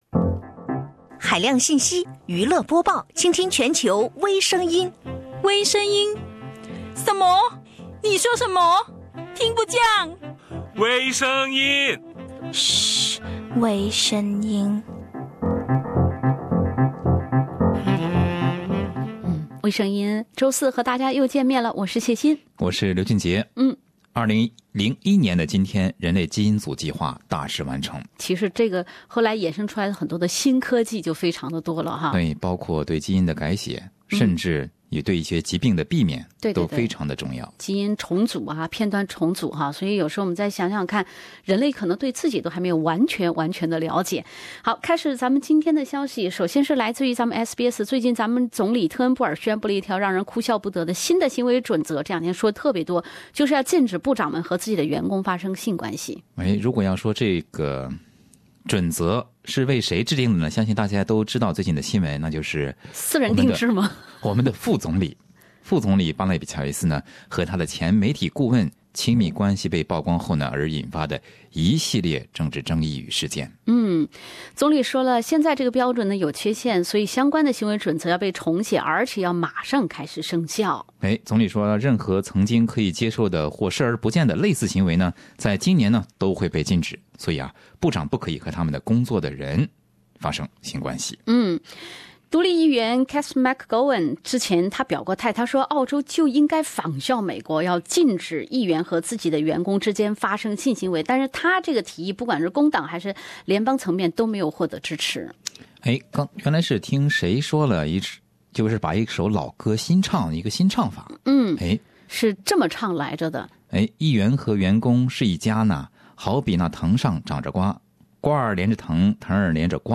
部长准则私人定制，跑车上天亿万旅程；邮轮度假目瞪口呆，真人配对大惊大喜。另类轻松的播报方式，深入浅出的辛辣点评；包罗万象的最新资讯；倾听全球微声音。